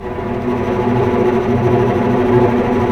Index of /90_sSampleCDs/Roland L-CD702/VOL-1/STR_Vcs Tremolo/STR_Vcs Trem p